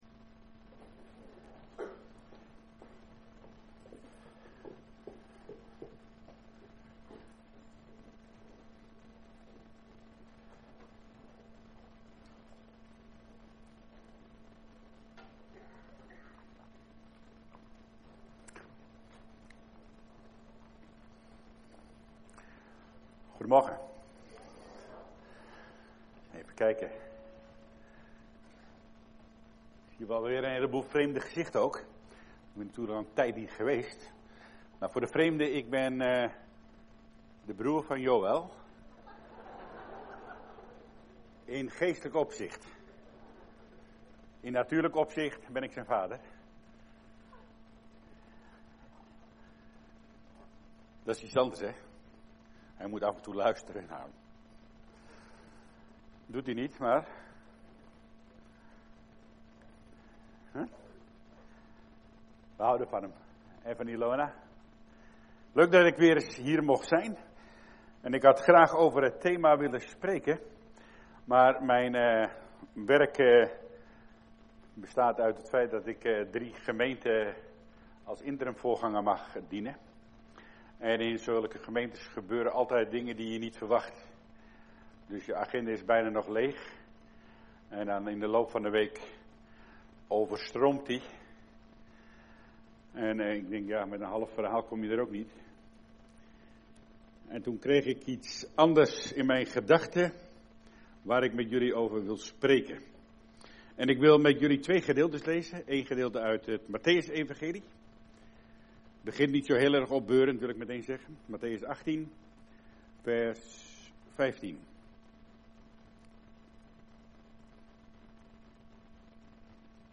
Preek
We komen elke zondagmorgen bij elkaar om God te aanbidden.